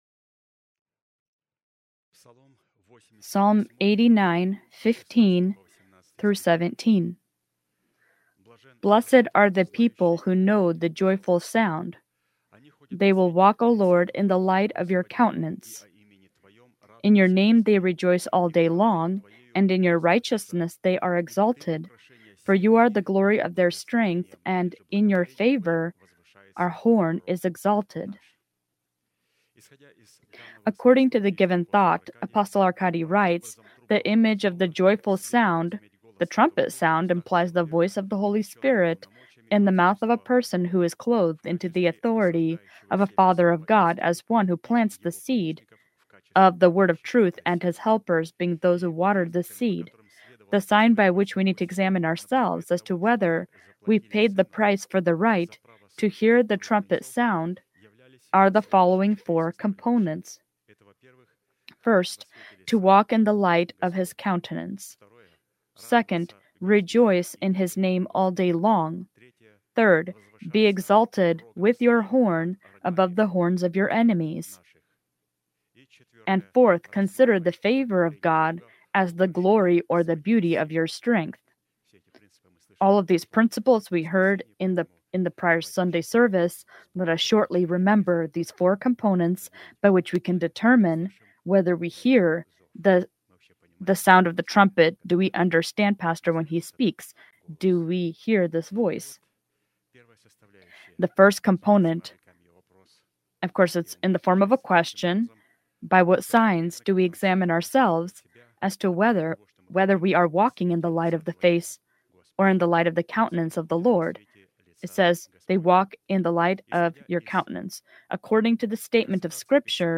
Служение: Воскресенье
Sermon title: Tithes